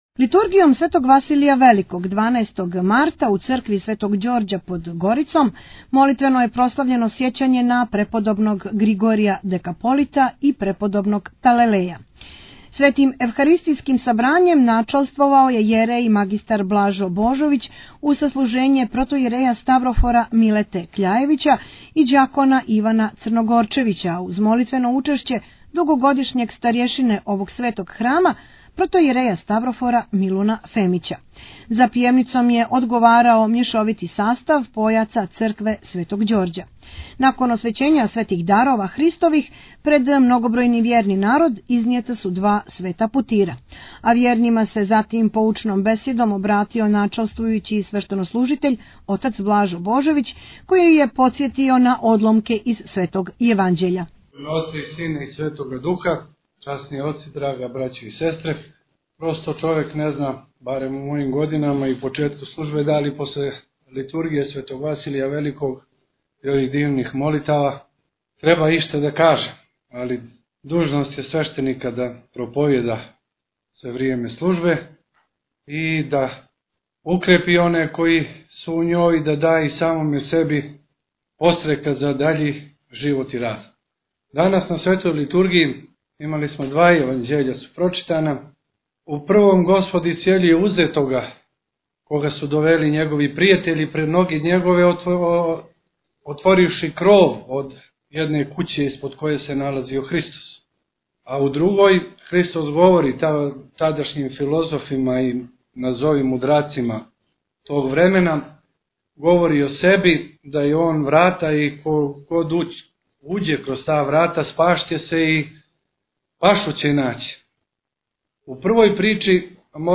У Недјељу пачисту одслужена Литургија у цркви Светог Ђорђа у Подгорици | Радио Светигора
Бесједе